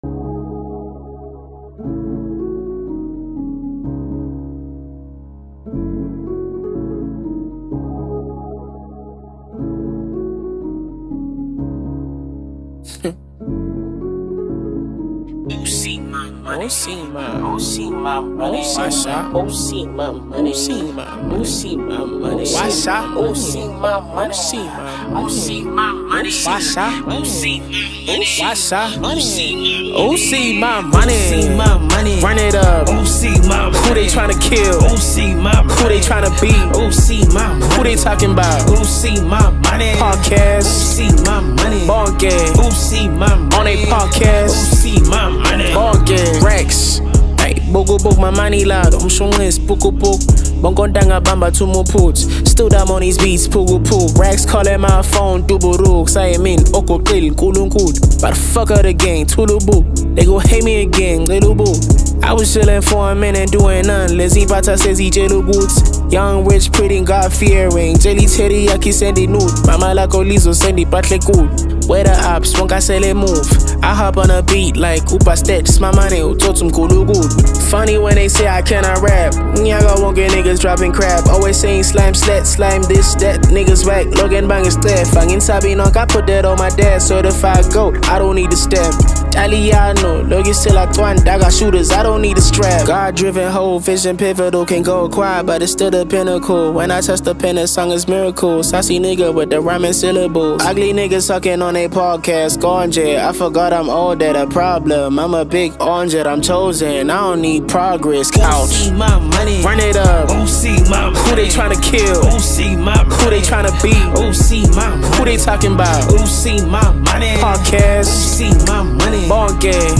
Home » Amapiano » DJ Mix